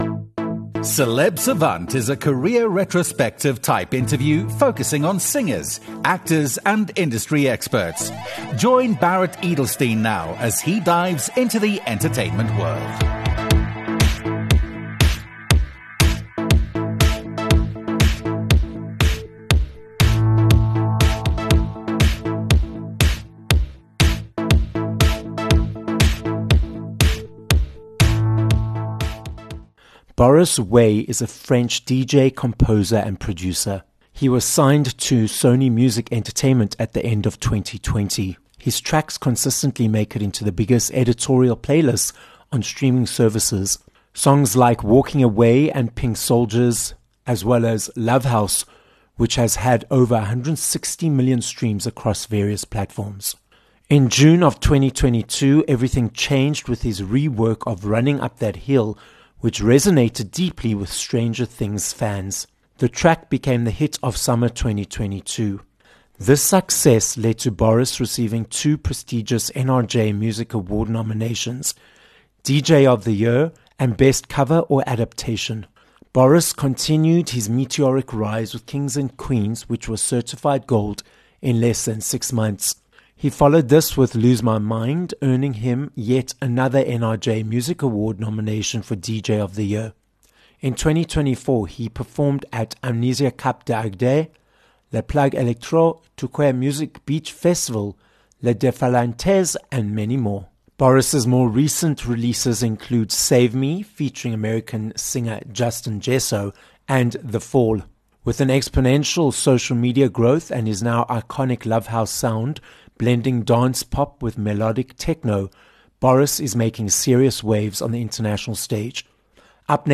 Boris Way - a French DJ, composer, producer, and NRJ Music Award nominee - joins us on this episode of Celeb Savant. Boris explains how he discovered dance music on a family trip to Ibiza, how the Covid lockdown allowed him to reset his artistic direction (leading to 3 gold singles), and how he creates music.